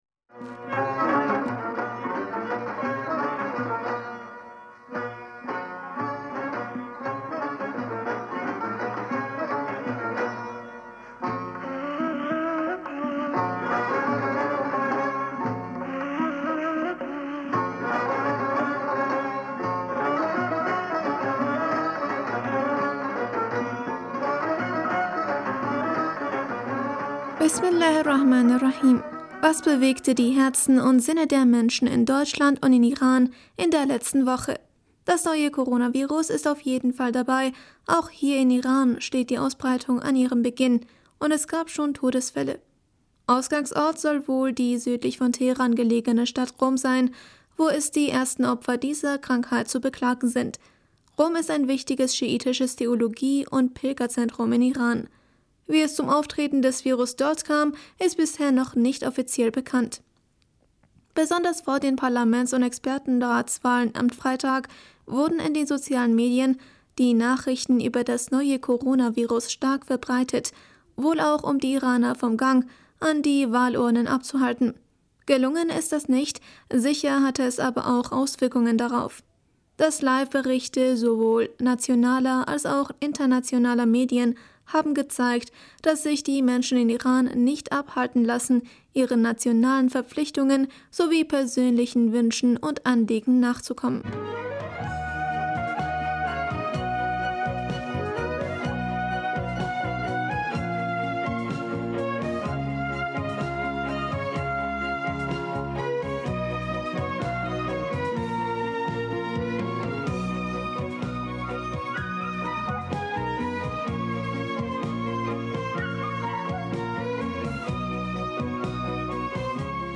Hörerpostsendung am 23. Februar 2020 Bismillaher rahmaner rahim - Was bewegte die Herzen und Sinne der Menschen in Deutschland und in Iran in der letzten...